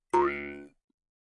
口腔竖琴第一卷 " 口腔竖琴12 短节奏
描述：口琴（通常被称为“犹太人的竖琴”）调到C＃。 用RØDENT2A录制。
Tag: 竖琴 曲调 口竖琴 共振峰 仪器 传统 jewsharp 共振峰 弗利